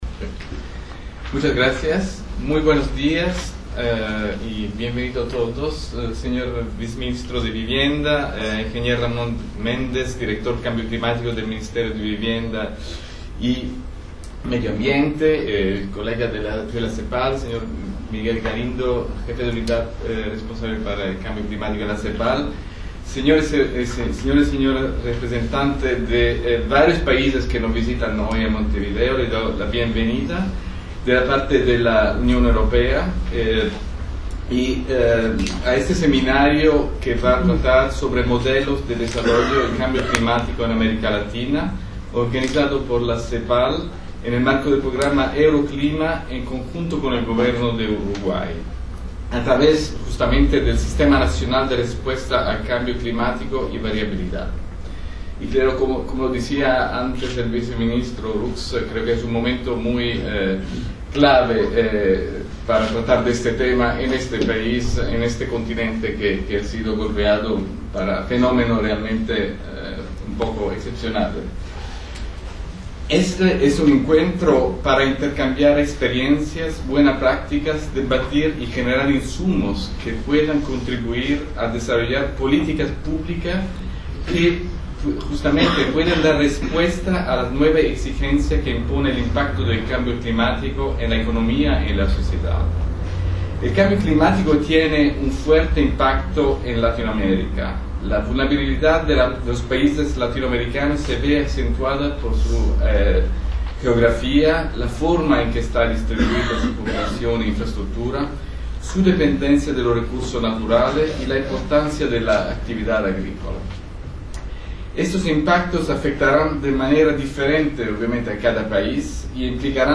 Discursos de la ceremonia de apertura